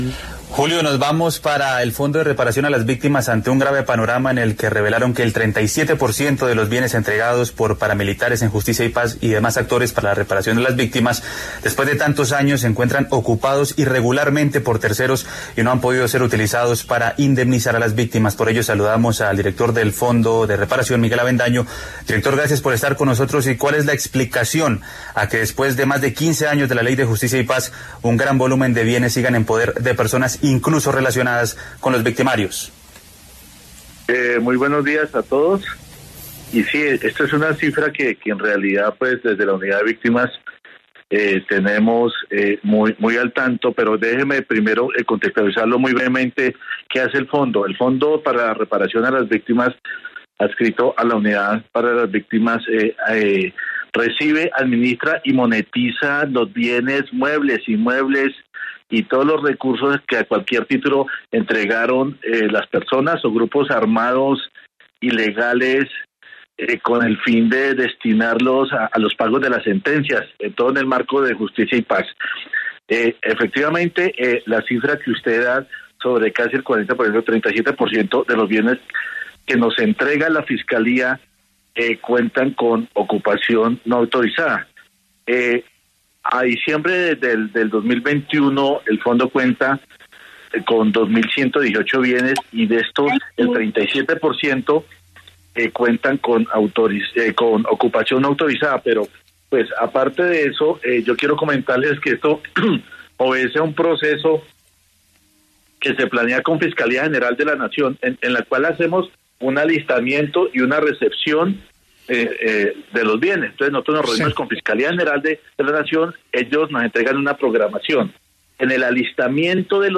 En entrevista con La W, el director del Fondo para la Reparación Miguel Avendaño señaló a la Fiscalía y a los magistrados de Justicia y Paz por entregarles predios que no han sido saneados.
Habló en La W Miguel Avendaño, director del Fondo de Reparación de las Víctimas sobre el estado de entrega de los bienes y el proceso para ello